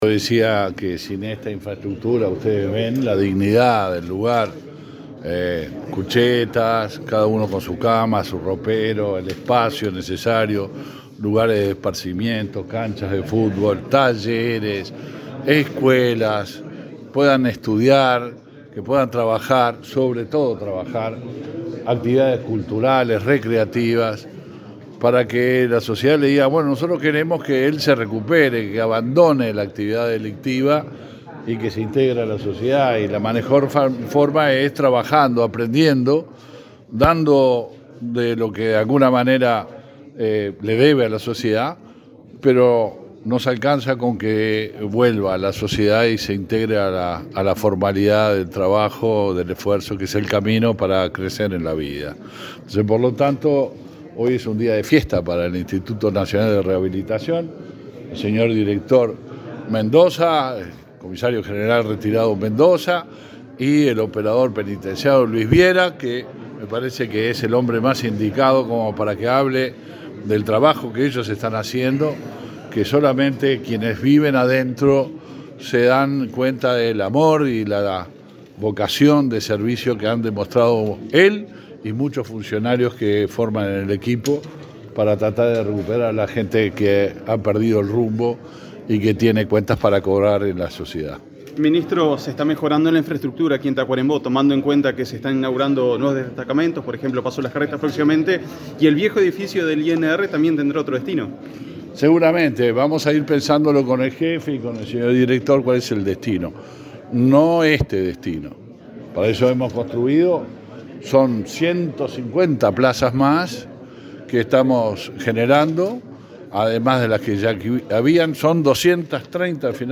El ministro del Interior, Luis Alberto Heber, dialogó con la prensa, luego de inaugurar la cárcel de Tacuarembó.